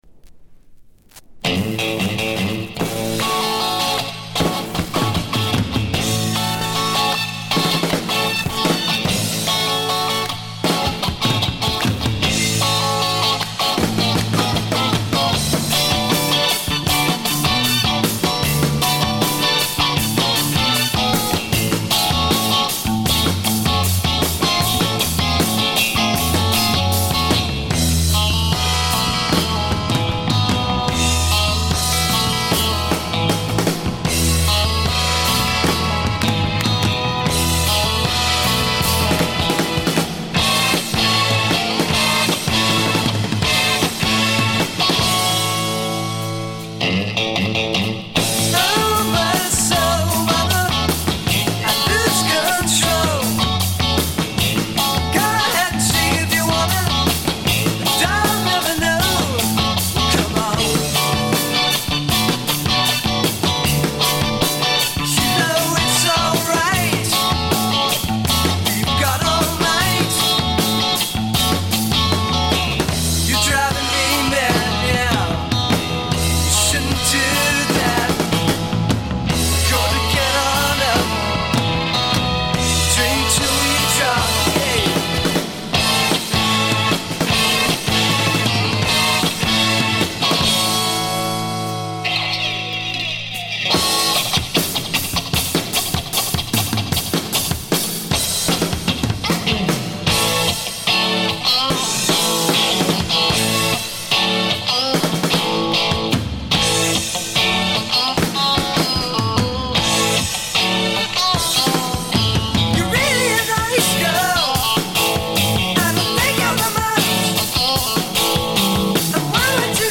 散発的なプツ音が3ヶ所ほど（ほとんど気付かないレベル）。
試聴曲は現品からの取り込み音源です。
guitar, vocals
drums, vocals